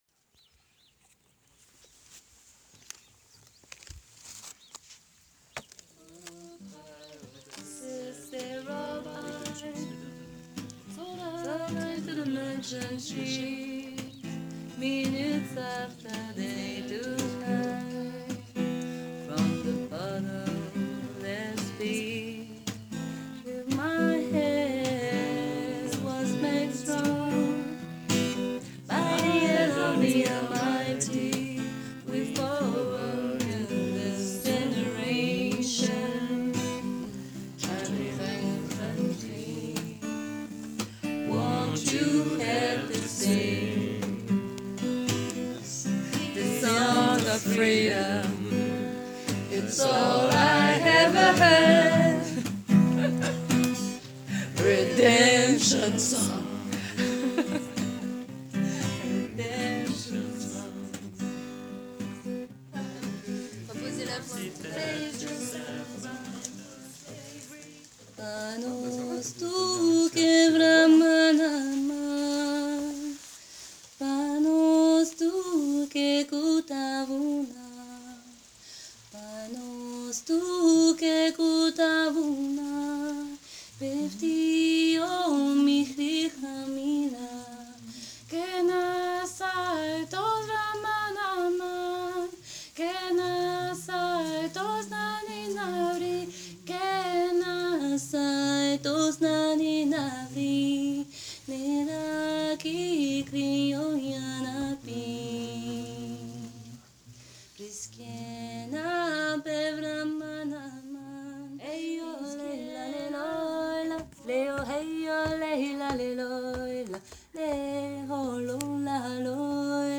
Chanter en tribu pour partager
Chanter pour communiquer par delà les mots, s’offrir soin ensemble, dans la serre au milieu des fleurs qui s’épanouissent. Laisser sortir de nos corps les sons qui composent le Monde, mélanger la voix humaine à la voix grenouille pour se relier, se comprendre dans les vibrations.